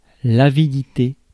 Ääntäminen
IPA : /griːd/